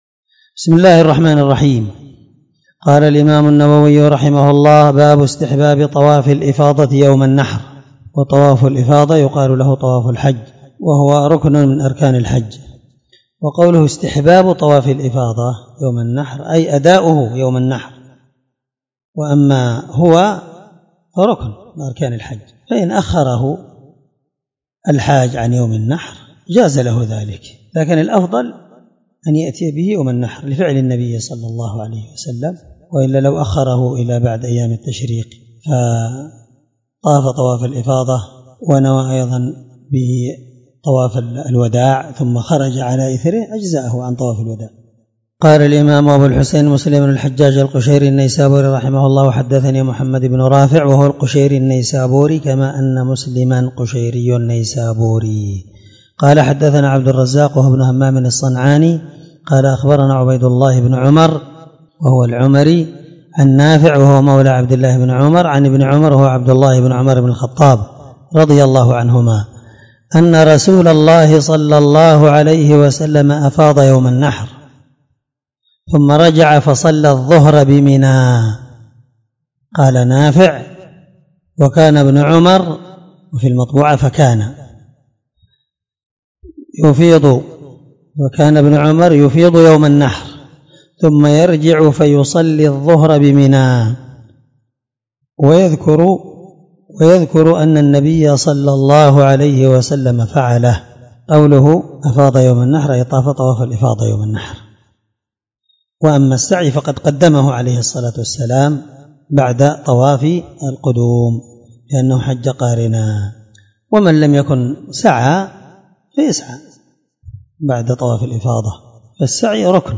الدرس54من شرح كتاب الحج حديث رقم(1308-1309) من صحيح مسلم